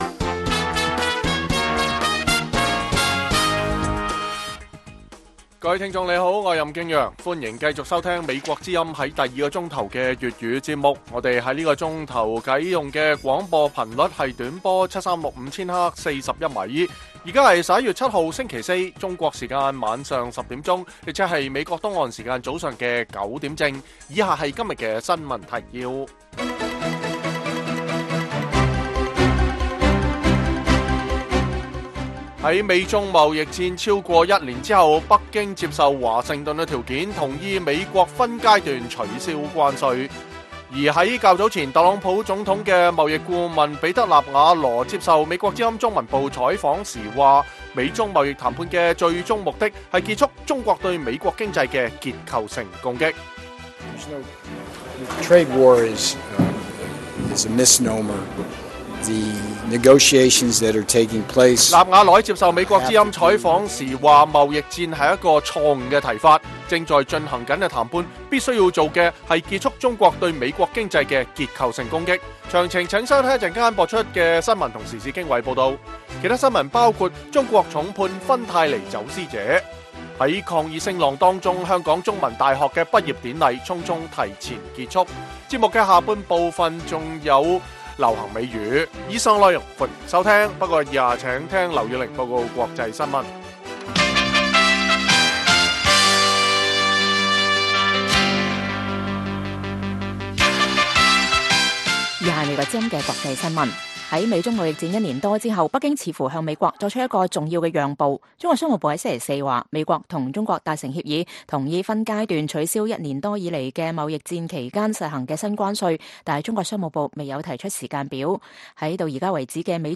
粵語新聞 晚上10-11點
北京時間每晚10－11點 (1400-1500 UTC)粵語廣播節目。內容包括國際新聞、時事經緯、英語教學和社論。